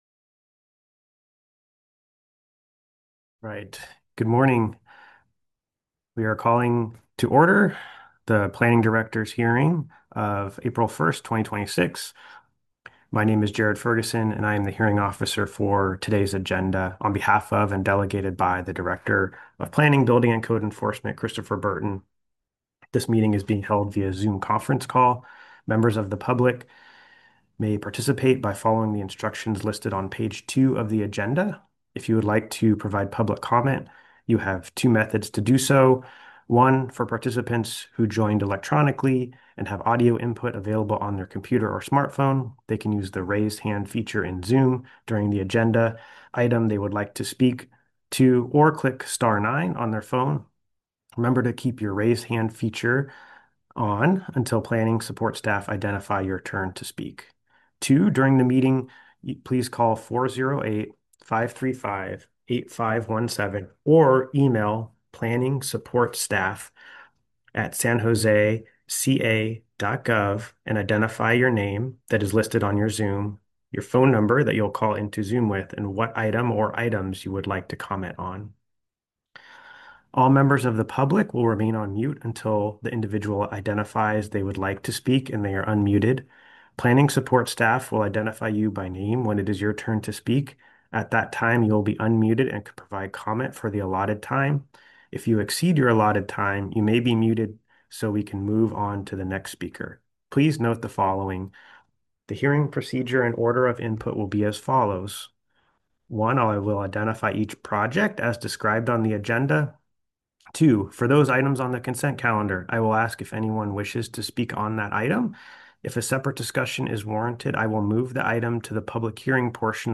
Produced directly by the City of San Jose, this government series offers a raw and unfiltered look into municipal processes, public discussions, and official meetings from that period. You'll hear authentic audio capturing the voices of city officials, community leaders, and residents as they grapple with the local issues of the day.